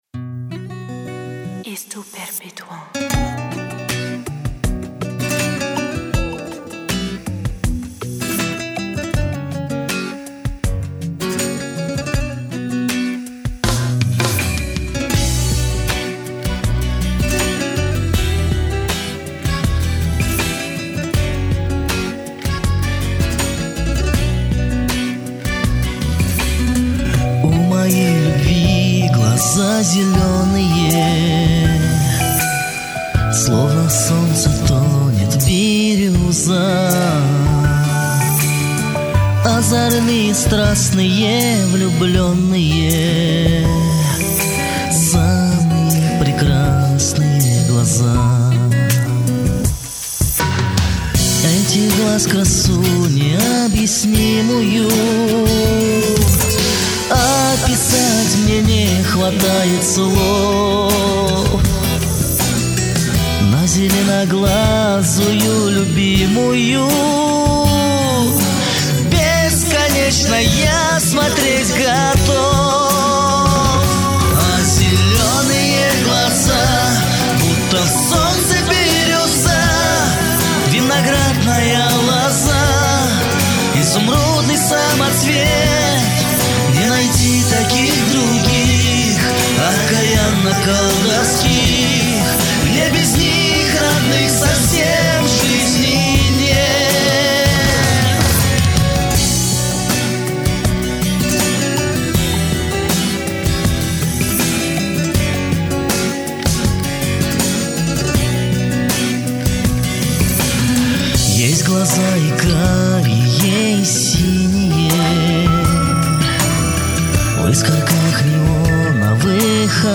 Поёт на разрыв души!!!